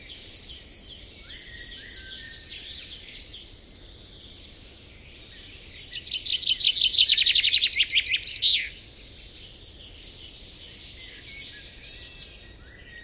bird.ogg